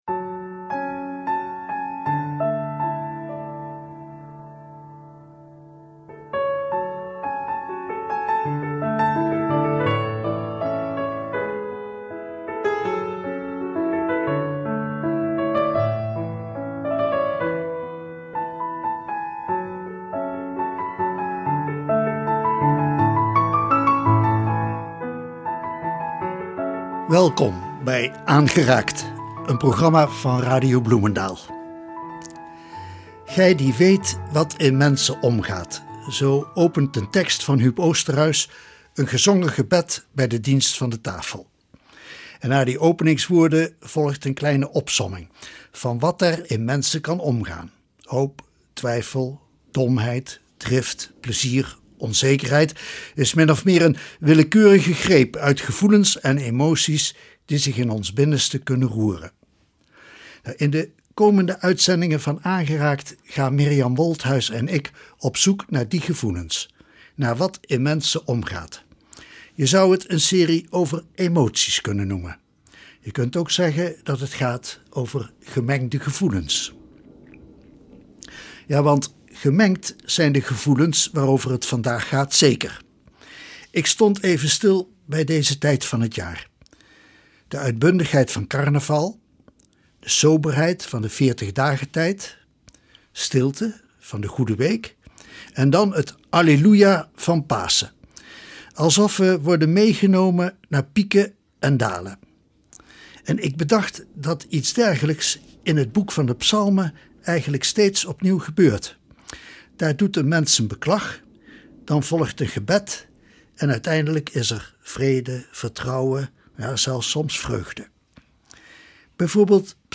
In deze aflevering kunt u luisteren naar teksten, liederen en overwegingen rond deze gemengde gevoelens.